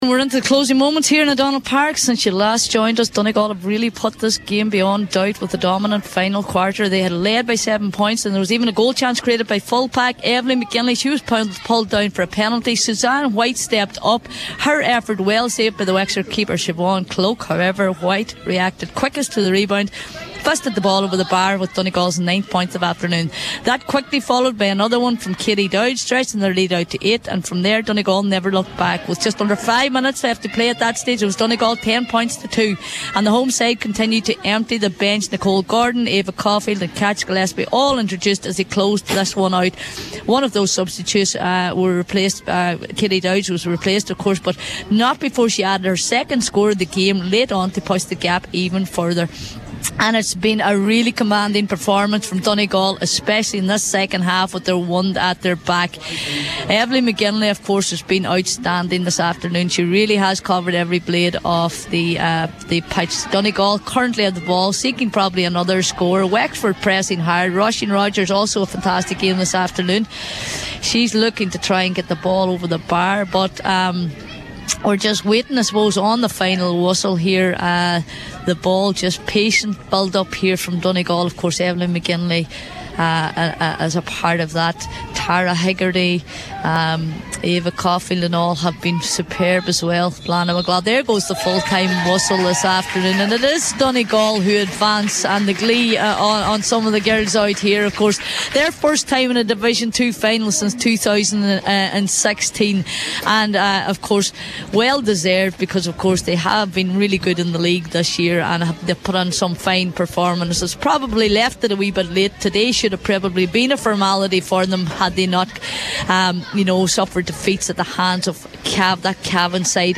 as the full time whistle approached in Letterkenny…